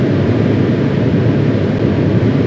Add combustion sound